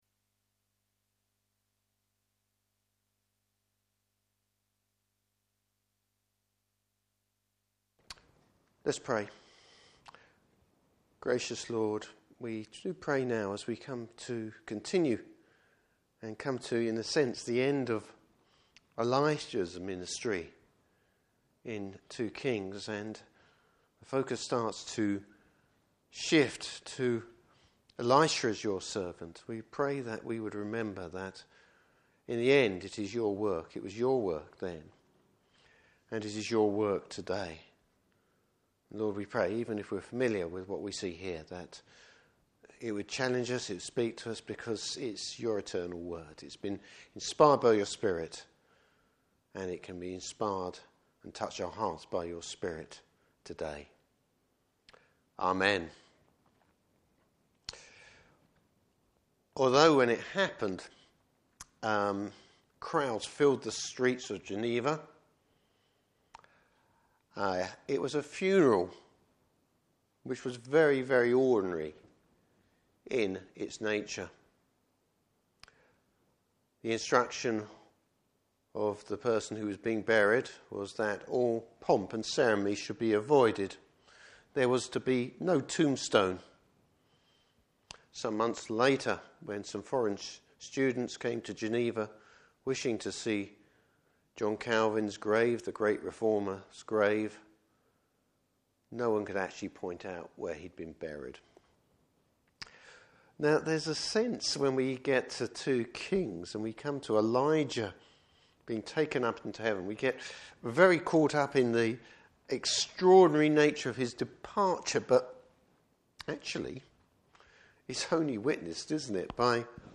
Service Type: Evening Service Bible Text: 2 Kings 2:1-18.